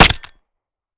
Home gmod sound weapons papa320
weap_papa320_fire_last_plr_mech_01.ogg